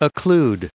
999_occlude.ogg